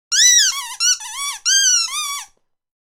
Squeaky Puppy Toy Sound Effect
Squeaky rubber dog toy sound effect – squeaking duck or chicken toy for dogs. Funny sounds.
Squeaky-puppy-toy-sound-effect.mp3